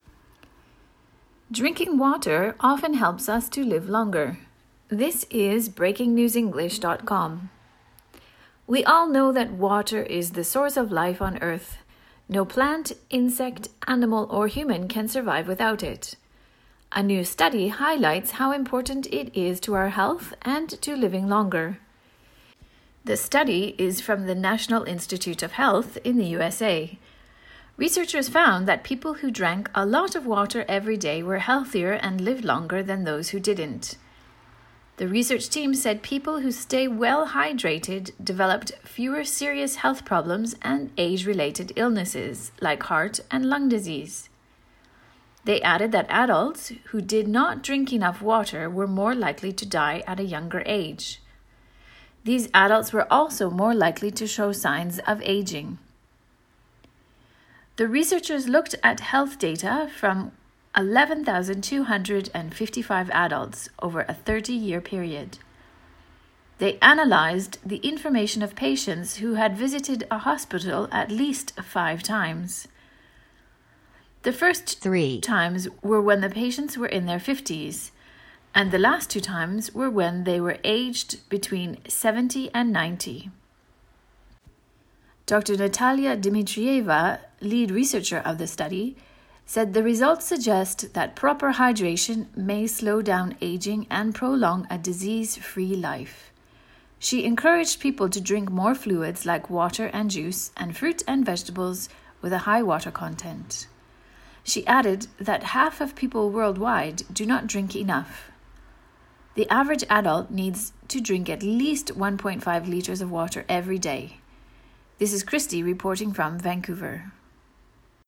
AUDIO((Normal)